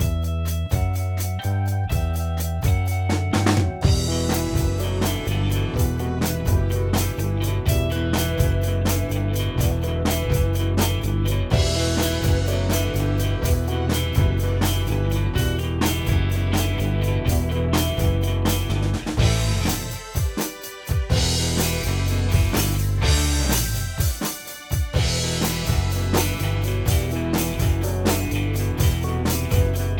no_vocal.wav